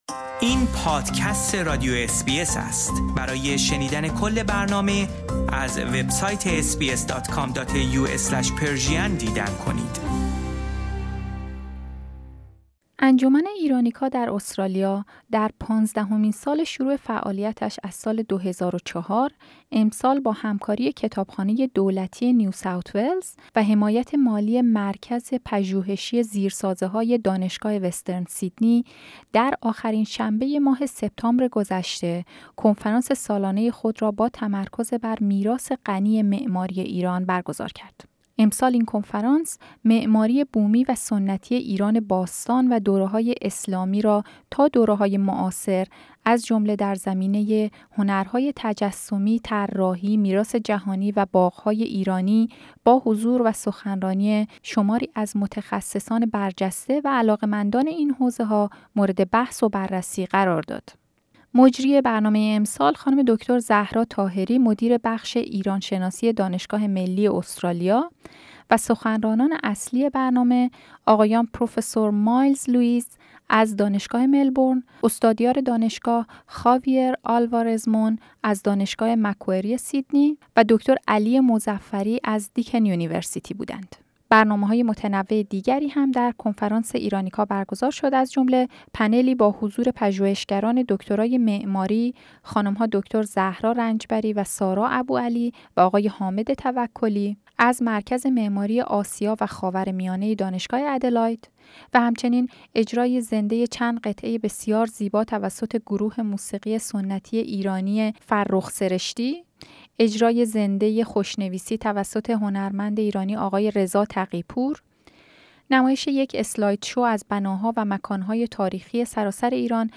انجمن ایرانیکا در استرالیا در پانزدهمین سال شروع فعالیتش از سال 2004، امسال با همکاری کتابخانه دولتی نیو ساوت ولز و حمایت مالی دانشگاه وسترن سیدنی، مرکز مهندسی زیرساختها، در آخرین شنبه ماه سپتامبر گذشته، کنفرانس سالانه خود را با تمرکز بر میراث غنی معماری ایران برگزار کرد. این گزارش، نگاه کوتاهی است به این کنفرانس و شامل گفتگوهایی است که در حاشیه برنامه با تعدادی از سخنرانان، هنرمندان و حامیان این برنامه داشتم.